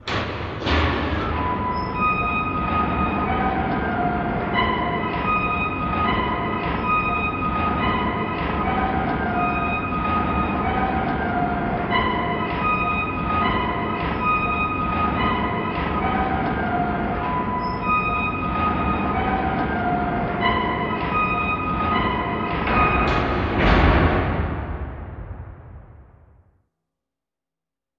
Гул раскрывающихся массивных металлических ворот средневековой крепости